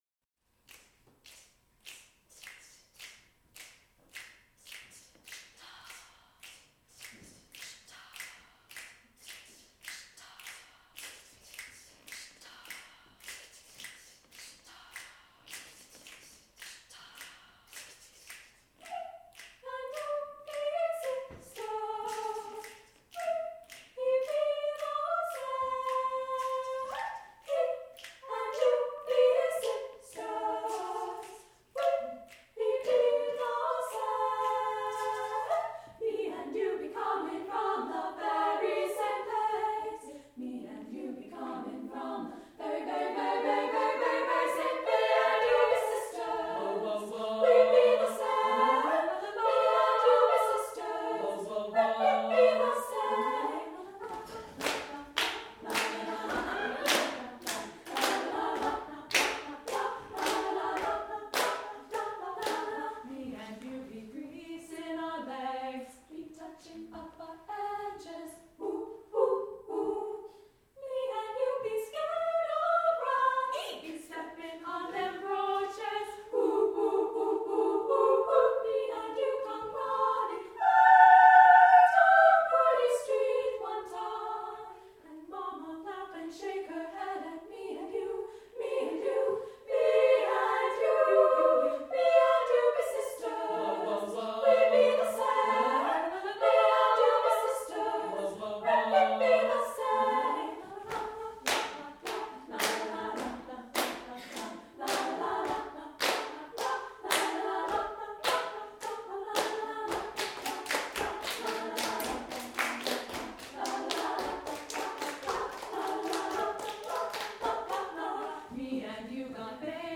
for SSA Chorus (1998)
There is energy and humor in both the poetic and musical language.